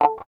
74 GTR 4  -R.wav